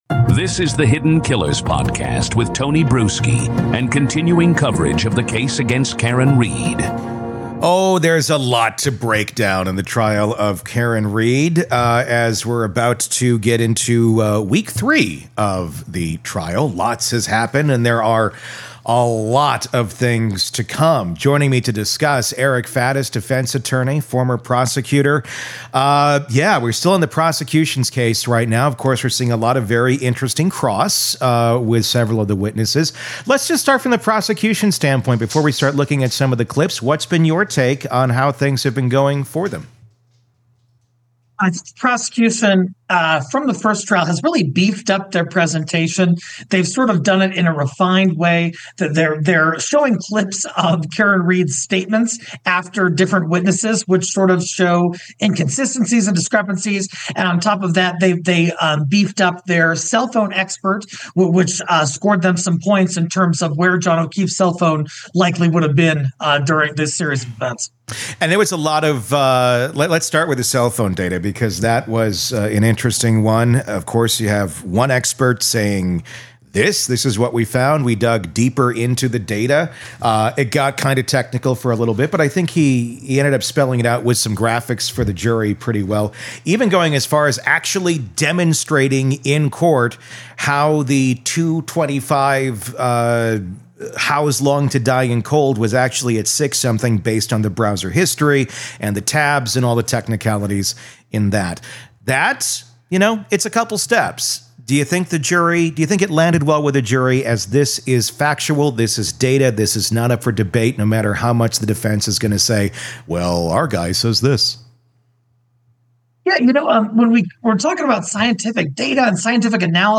In this episode, we're joined by criminal defense attorney and former prosecutor